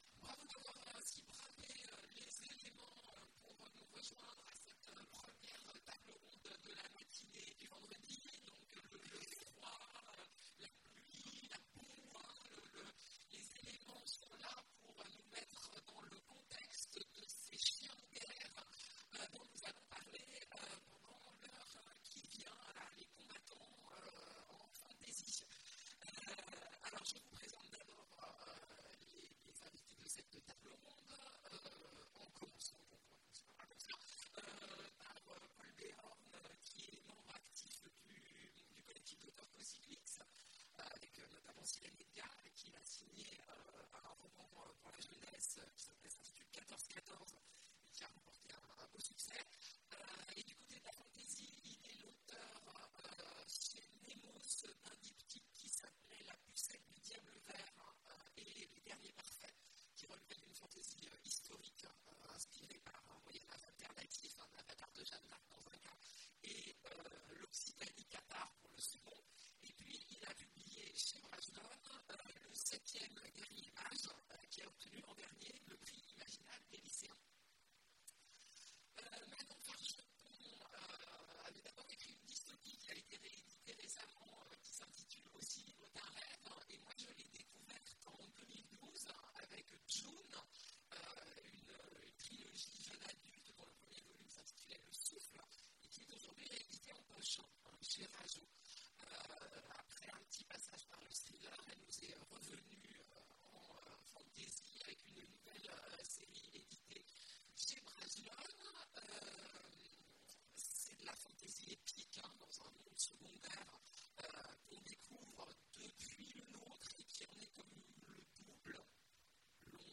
Mots-clés Fantasy Conférence Partager cet article